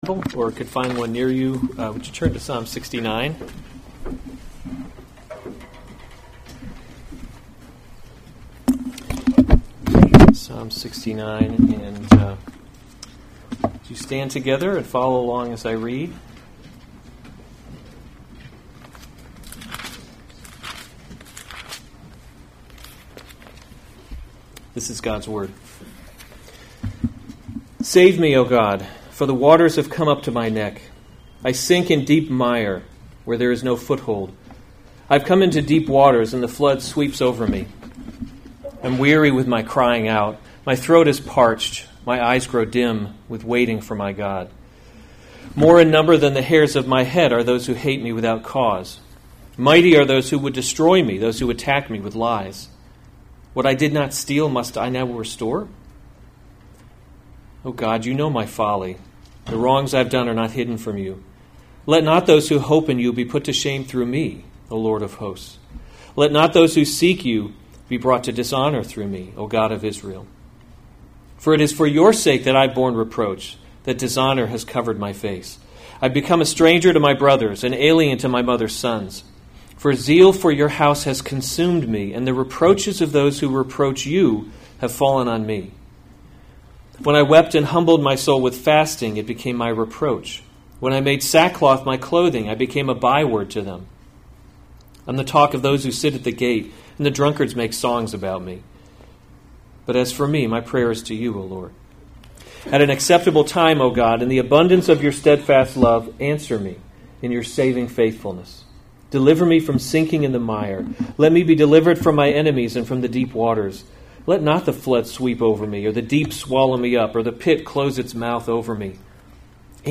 August 17, 2019 Psalms – Summer Series series Weekly Sunday Service Save/Download this sermon Psalm 69 Other sermons from Psalm Save Me, O God To the choirmaster: according to Lilies.